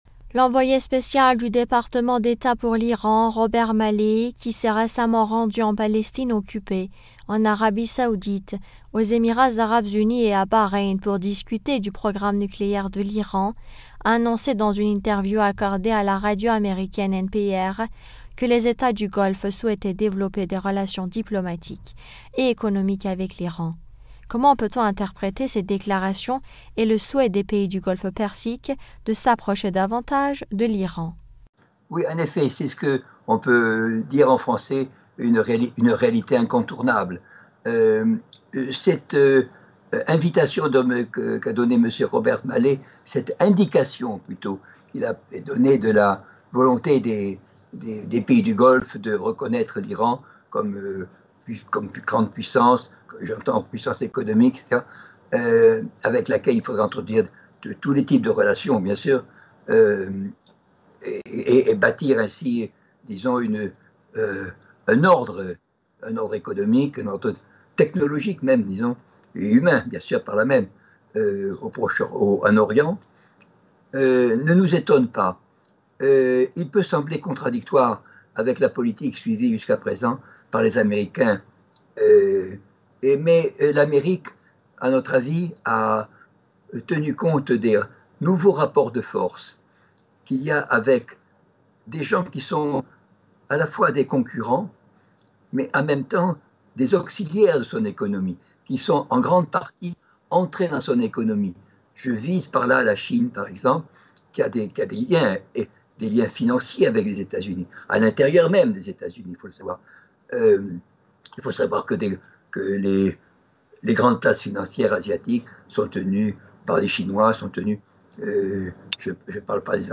politologue s'exprime sur le sujet.